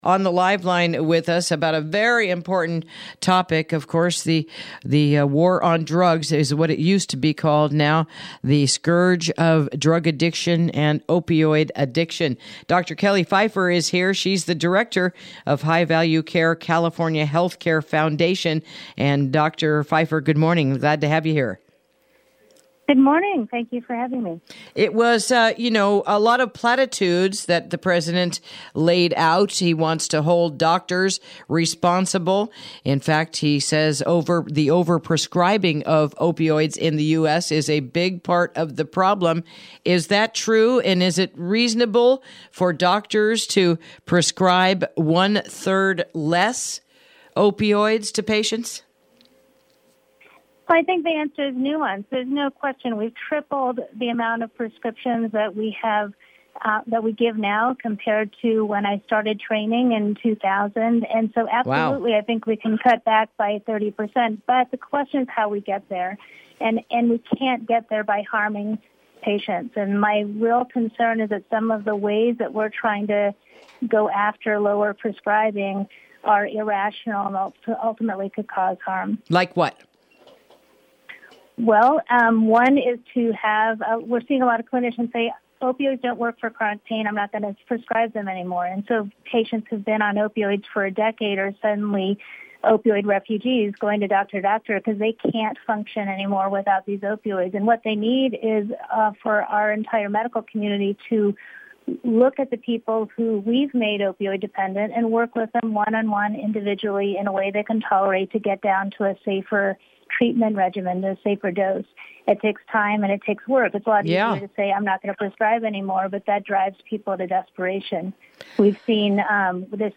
Interview: The President’s Opioid Plan and the State of Prescribing Pain Medications